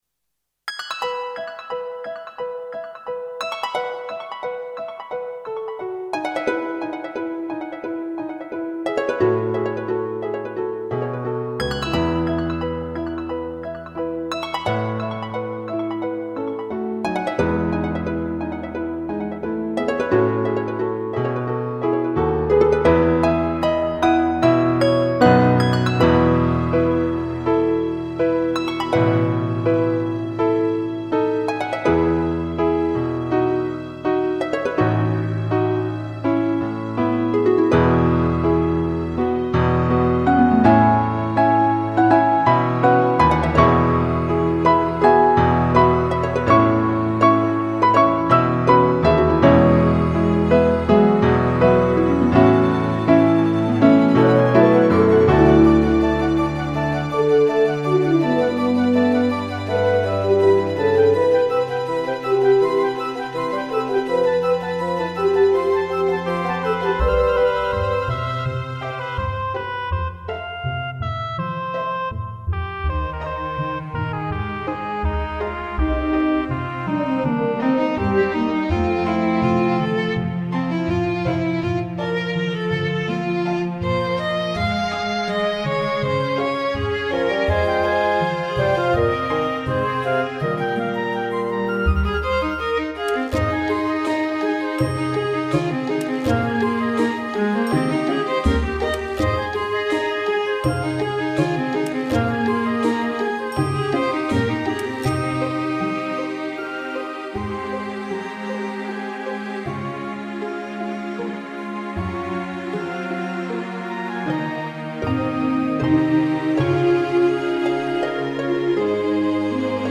klassiek